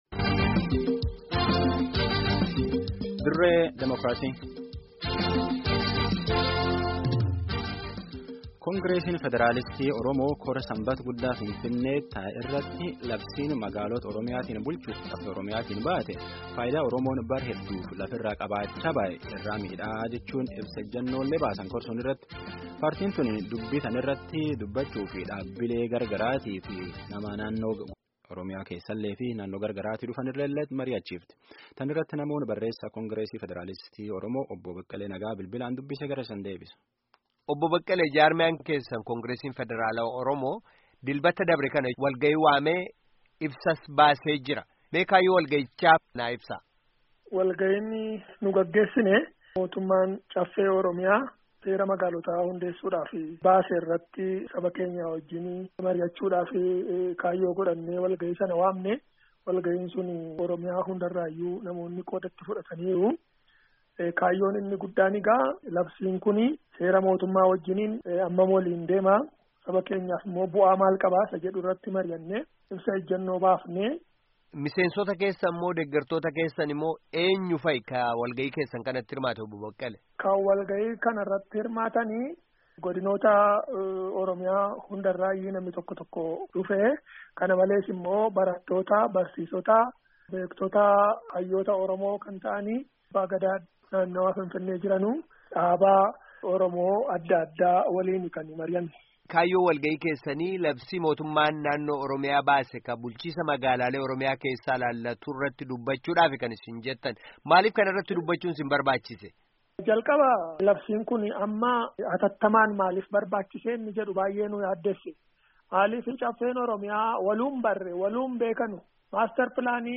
Guutummaa gaaffii fi deebii